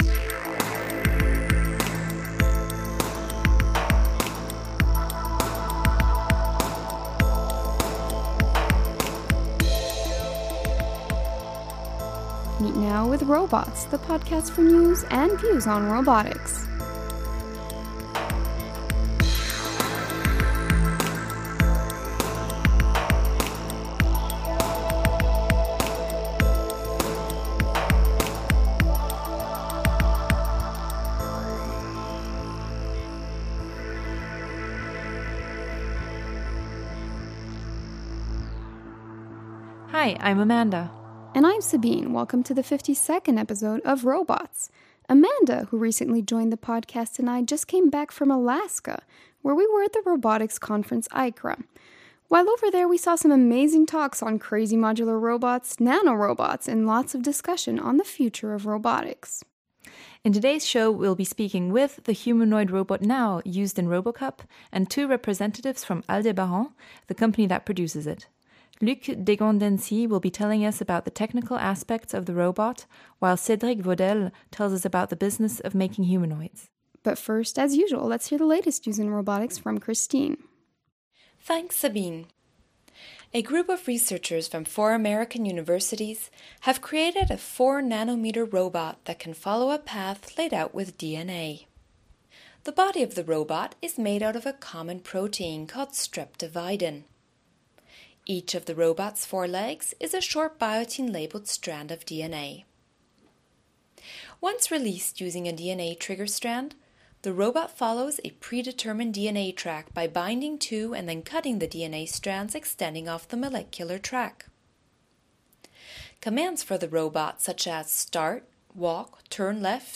Today’s episode was recorded at ICRA in Anchorage Alaska, one of the major conferences in robotics with a 1575 head count and 857 papers.
We also talk with Nao in our first ever interview of a robot! Nao will be presenting himself and his version of Star Wars.